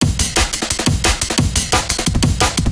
subsonic_amen3.wav